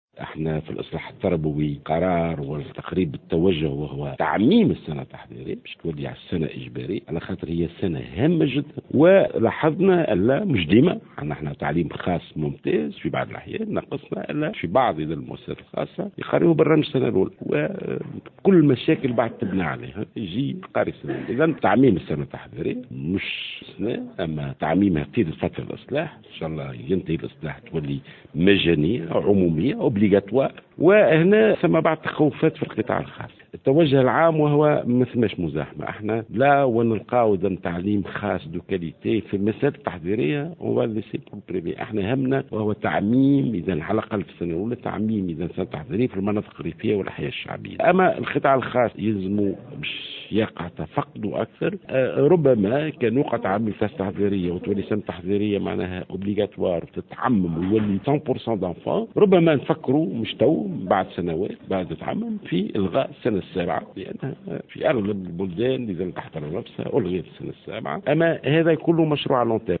وقال الوزير على هامش اللقاء الإعلامي الأول تحت شعار " نبادر ، نساند ، نطور من أجل الطفولة" " همنا الوحيد هو تعميم السنة التحضيرية في المناطق الريفية والأحياء الشعبية..و لو وقع تعميم السنة التحضيرية ربما نفكر بعد عدّة سنوات في إلغاء السنة السابعة."